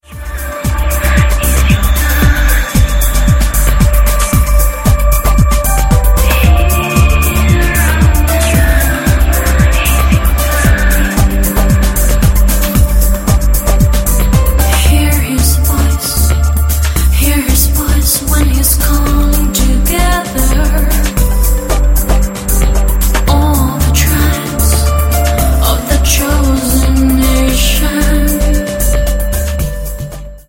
A Spititual Mix of Dance, Trance, Stadium and Club
• Sachgebiet: Dance